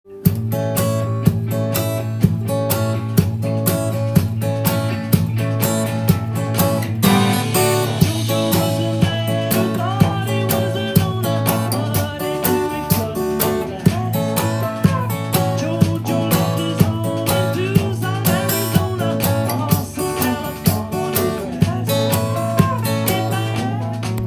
音源にあわせて譜面通りアコギで弾いてみました
パーカッシブなリズムを加えることが出来ます。
ドラムのバスドラとスネアのようなリズムを